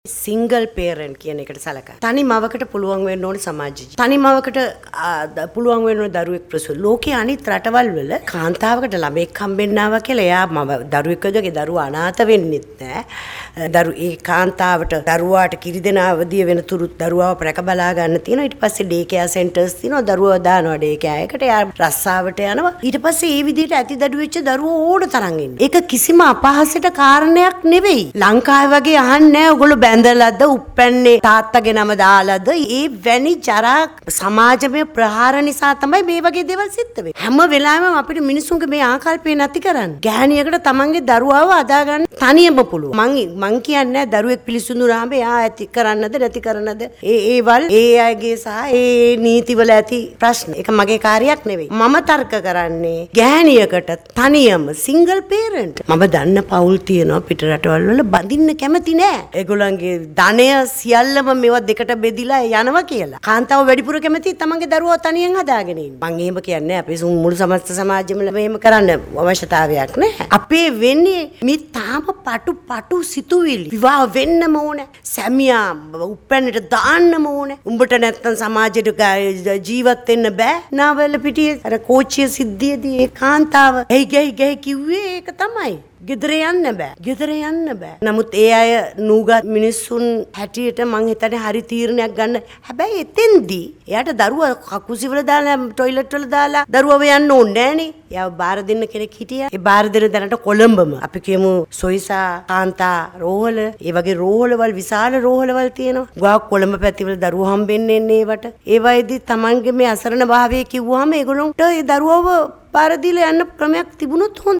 අද පැවති මාධ්‍ය හමුවකට එක්වෙමින් රාජ්‍ය අමාත්‍ය ගීතා කුමාරසිංහ මහත්මිය සදහන් කළේ අදාළ මධ්‍යස්ථානය කඩිනමින් ස්ථාපිත කිරිම සදහා ජනාධිපතිවරයා සමග සාකච්ඡා ආරම්භ කර ඇති බවයි.